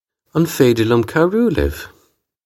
On fay-dir lyum kow-roo liv?
This is an approximate phonetic pronunciation of the phrase.